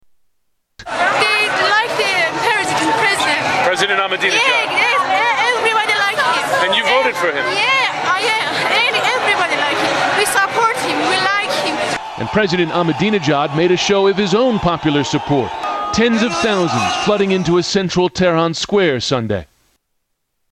Supporter of Mahmoud Ahmadinejad